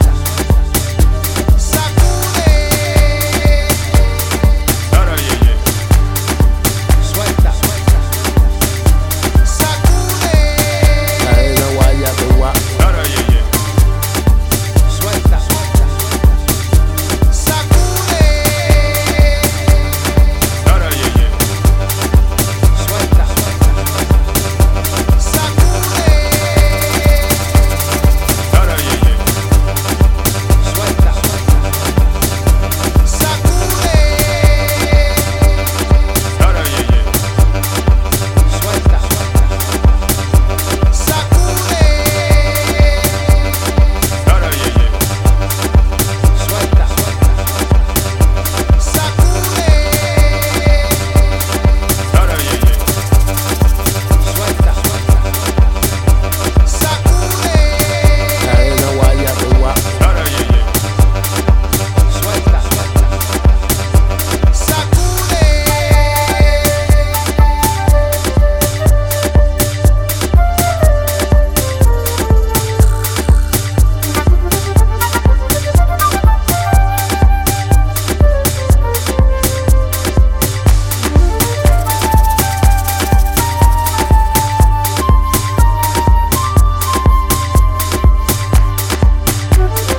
ジャンル(スタイル) DEEP HOUSE / AFRO HOUSE